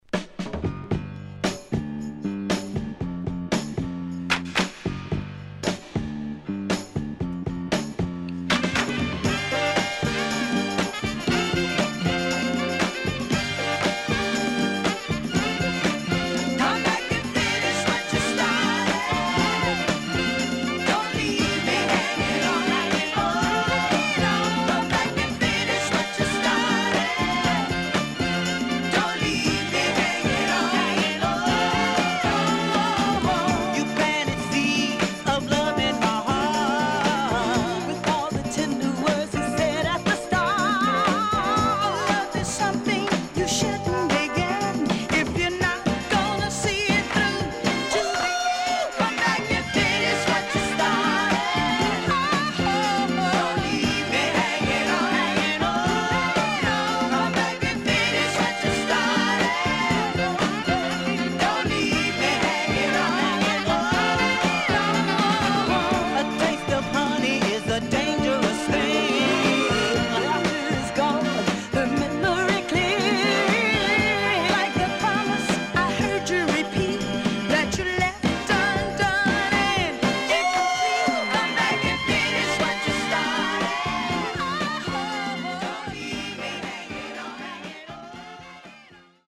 HOME > SOUL / OTHERS
SIDE B:少しチリノイズ入りますが良好です。